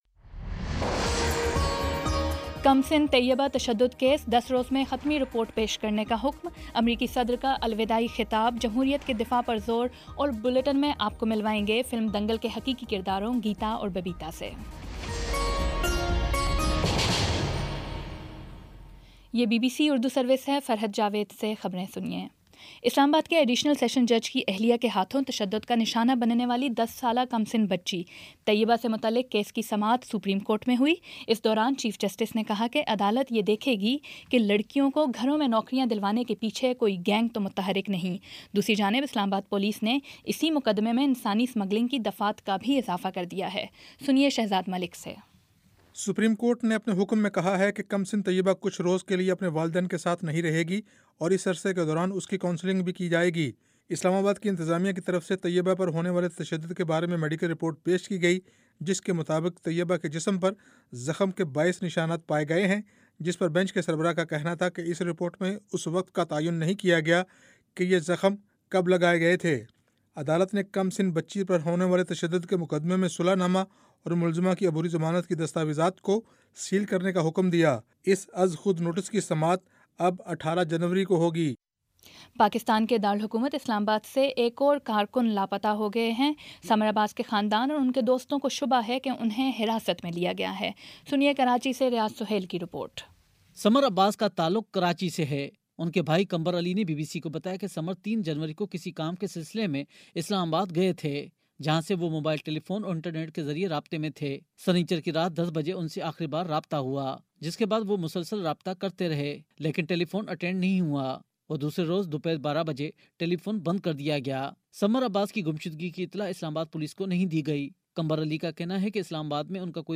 جنوری 11 : شام پانچ بجے کا نیوز بُلیٹن